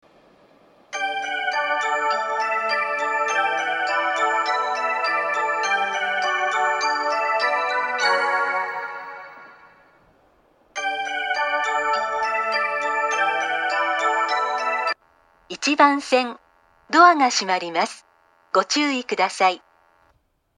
また、２０１０年３月以前に放送装置を更新し、メロディーに低音ノイズが被るようになりました。
伊東・伊豆高原・伊豆急下田方面   １番線接近放送
１番線発車メロディー